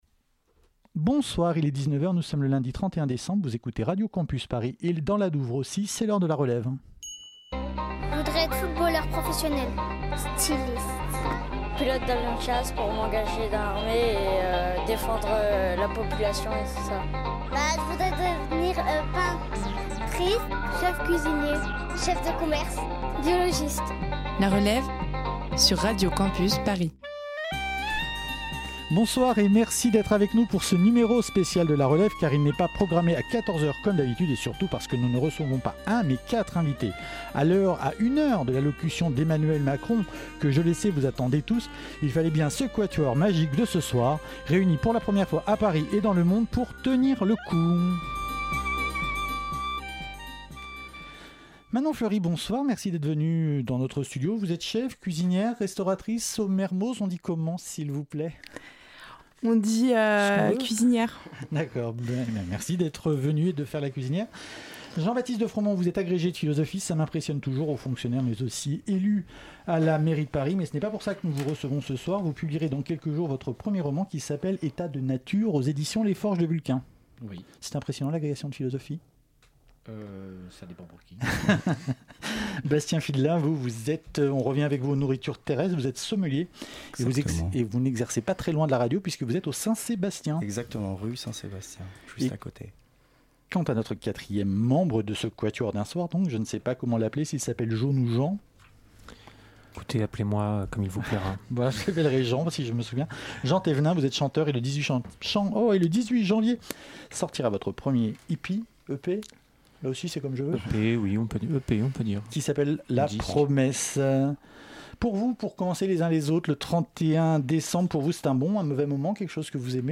Pour la dernière relève de 2018, on vous avait préparé une surprise : une émission d'une heure, et un cross over avec la matinale de 19H. On avait un quatuor d'invités exceptionnels.
Type Entretien